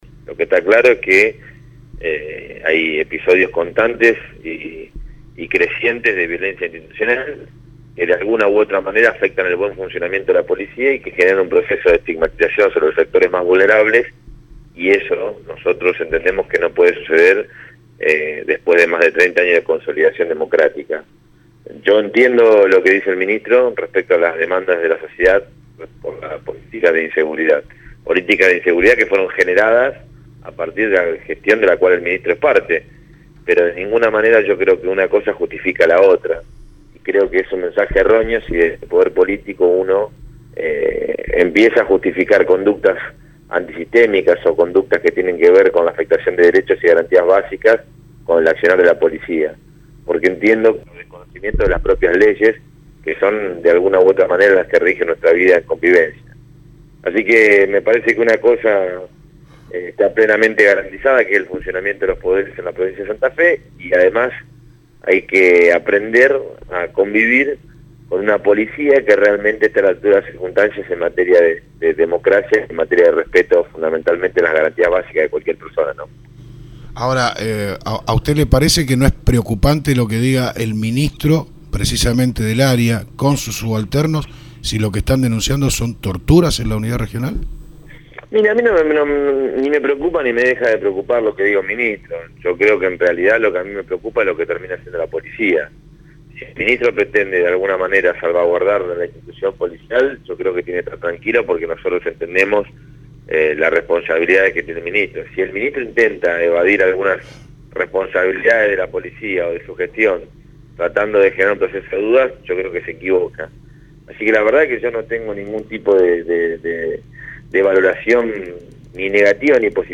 El Diputado Provincial (FPV) Leandro Busatto, en dialogo con FM ACTIVA respondió a los dichos del Ministro de Seguridad de la Provincia, Maximilano Pullaron que, en oportunidad de estar en la ciudad de Vera, dijo que no le preocupaba que venga una comisión de diputados a ver cómo trabaja la policía de la provincia de Santa Fe, en clara alusión a los testimonios que recogieron legisladores de la comisión de derechos y garantías de la legislatura respecto a las denuncias y abusos que se cometen en la U.R XIX por parte del personal policial.